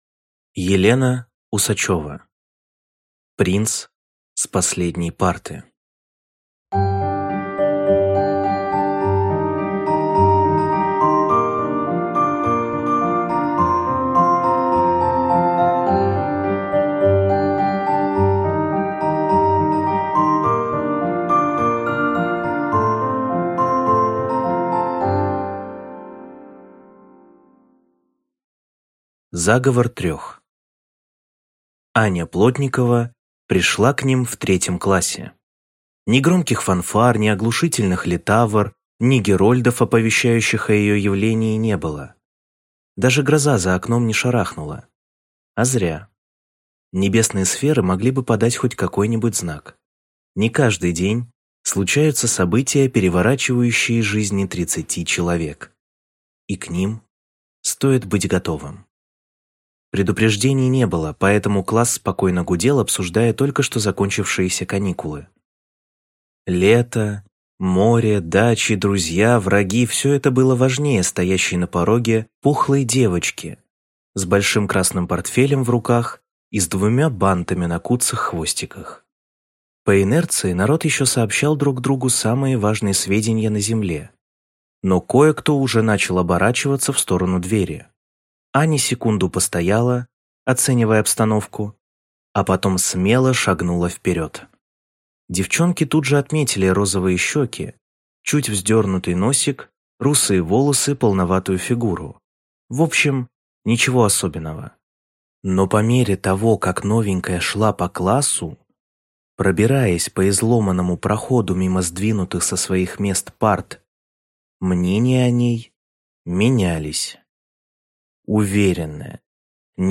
Аудиокнига Принц с последней парты | Библиотека аудиокниг